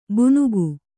♪ bunugu